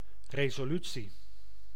Ääntäminen
IPA: /re.soˈly.tsi/